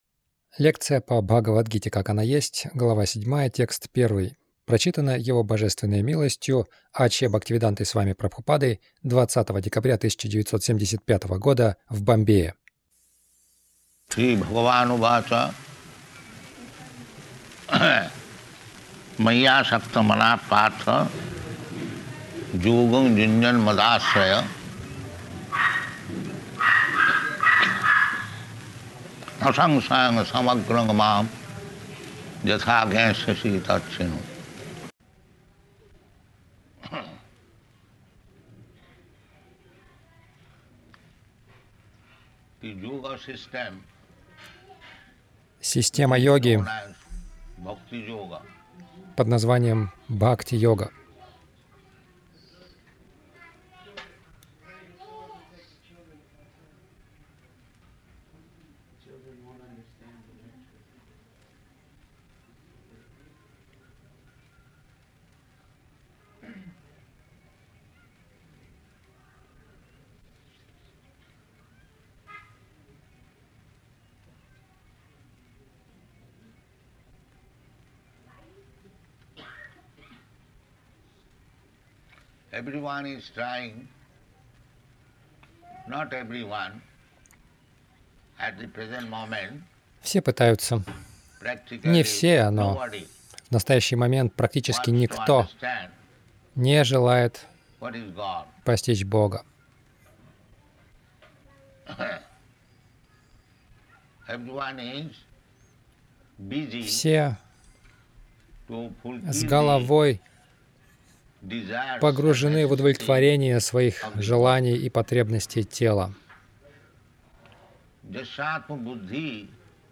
Милость Прабхупады Аудиолекции и книги 20.12.1975 Бхагавад Гита | Бомбей БГ 07.01 — Станьте гуру.